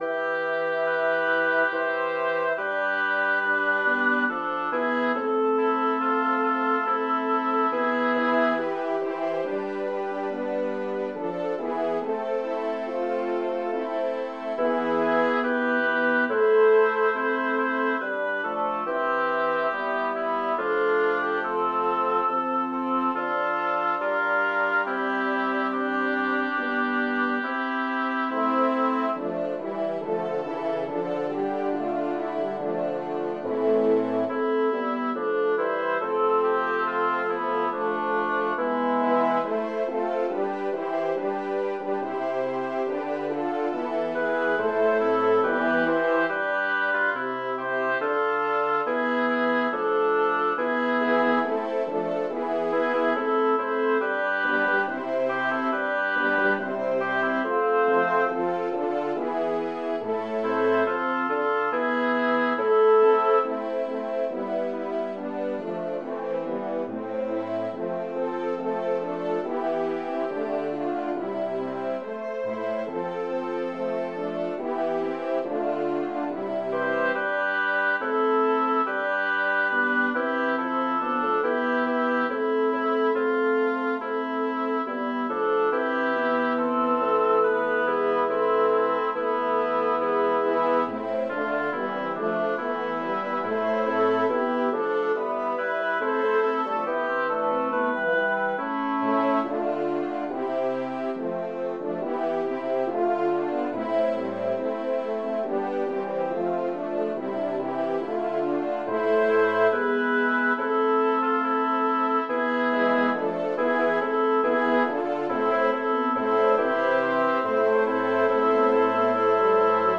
Title: O dulcissime Jesu Composer: Costanzo Porta Lyricist: Number of voices: 8vv Voicings: SATB.SATB or ATTB.ATTB Genre: Sacred, Motet
Language: Latin Instruments: A cappella